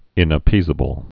(ĭnə-pēzə-bəl)